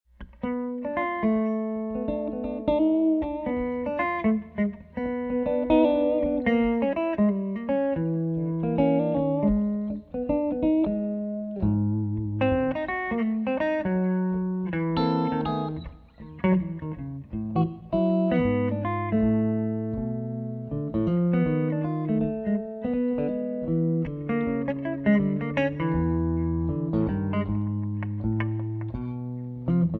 is medium tempo blues with a strong midwest feel.